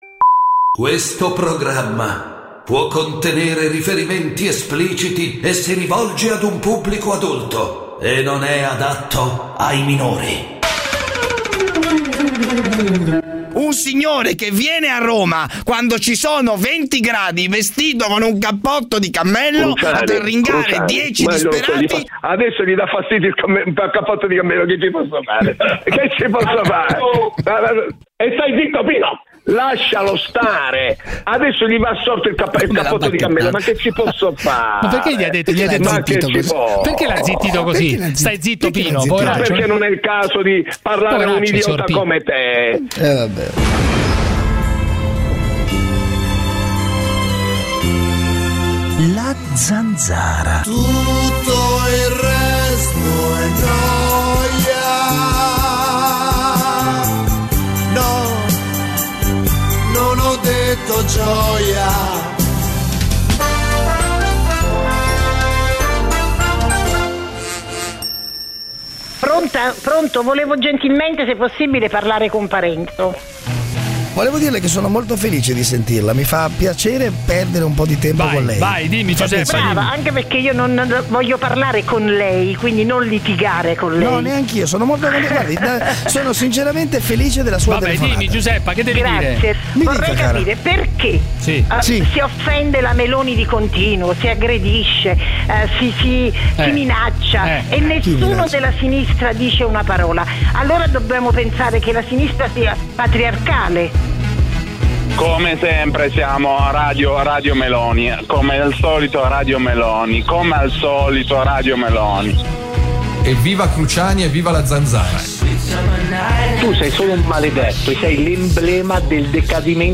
Giuseppe Cruciani con David Parenzo conduce "La Zanzara", l'attualità senza tabù, senza censure, senza tagli alle vostre opinioni. Una zona franca per gli ascoltatori, uno spazio nemico della banalità e del politicamente corretto, l'arena dove il primo comandamento è parlare chiaro.
… continue reading 2022 epizódok # Italia Attualità # News Talk # Notizie # Radio 24